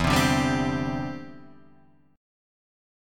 Em7#5 Chord